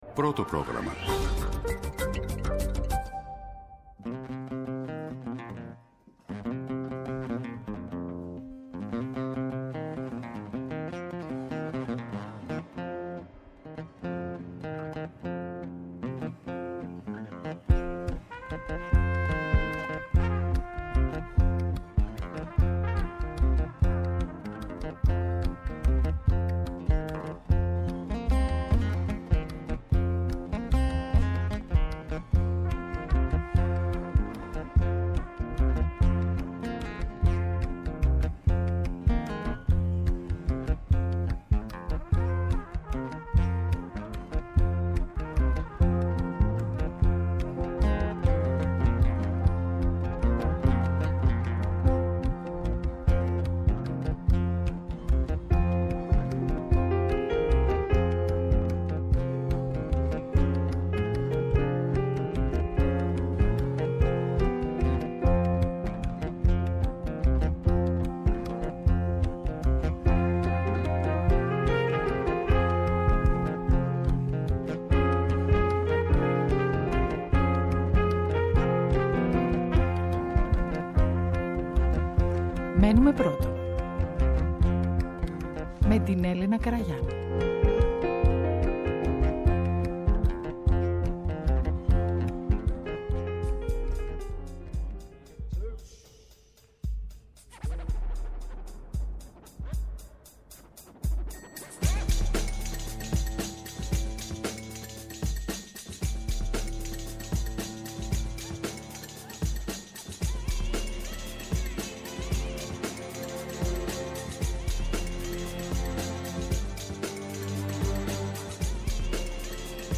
Ειδήσεις και απόψεις.
ΕΡΤNEWS RADIO